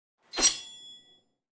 SWORD_26.wav